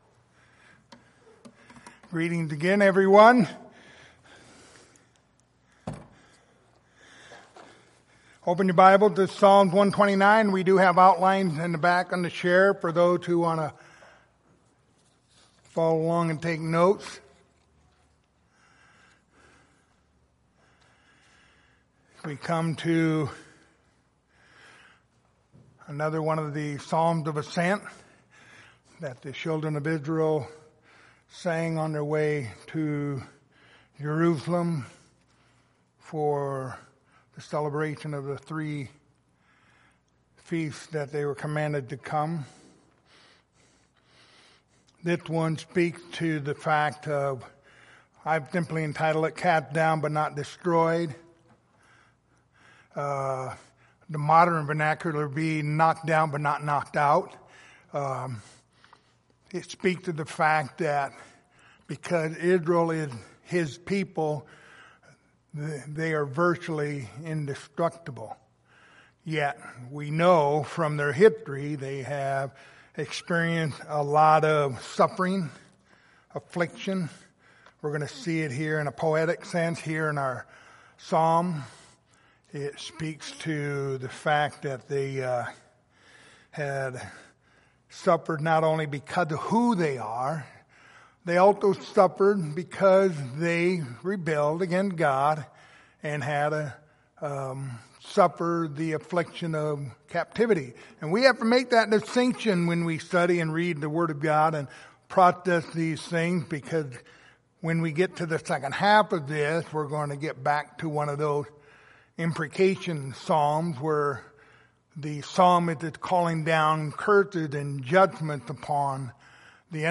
Passage: Psalm 129:1-8 Service Type: Sunday Evening